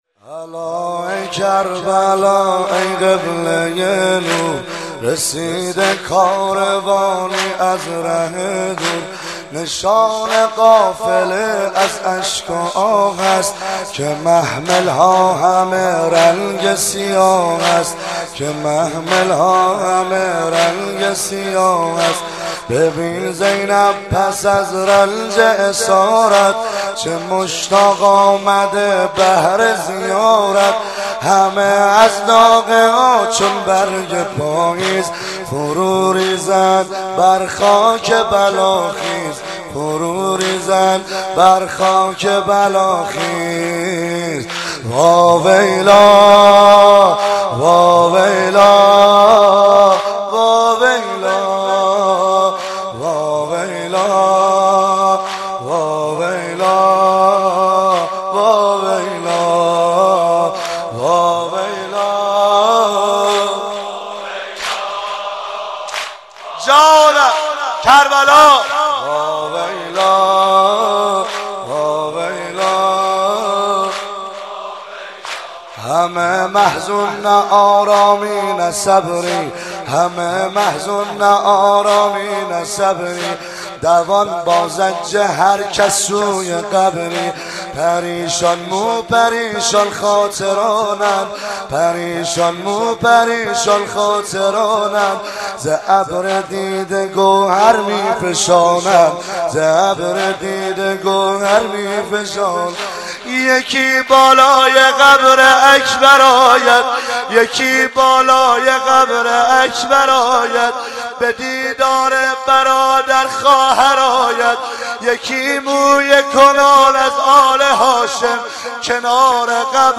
مدح امام حسن